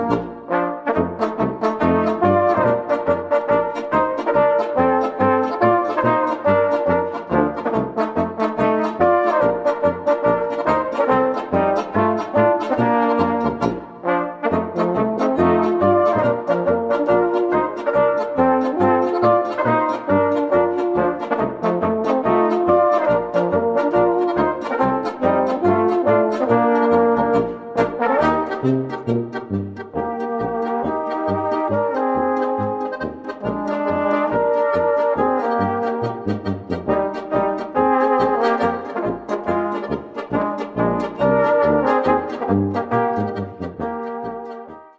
Gattung: Volksmusik natürlicher und ursprünglicher Art
Besetzung: Volksmusik/Volkstümlich Weisenbläser